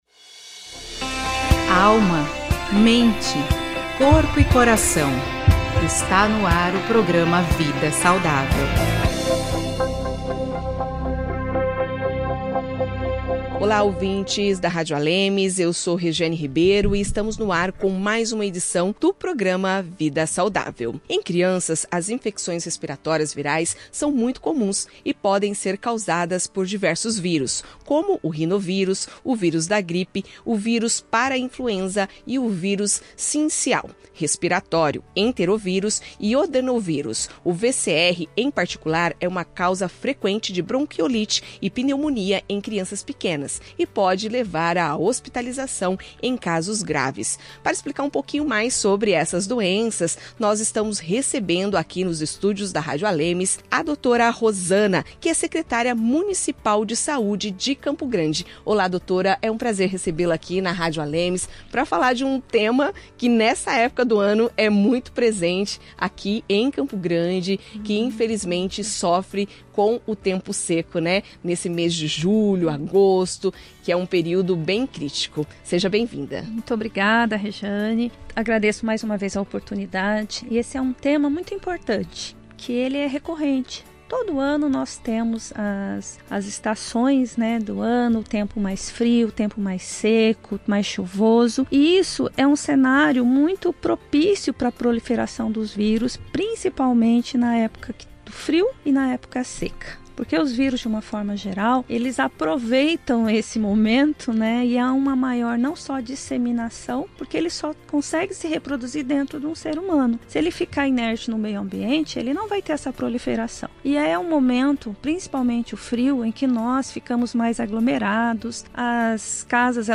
O Programa Vida Saudável, da Rádio ALEMS, entrevista a secretária de Saúde de Campo Grande, Rosana Leite de Melo, sobre infecções respiratórias virais em crianças, com destaque para o Vírus Sincicial Respiratório (VSR), causador de quadros como bronquiolite e pneumonia.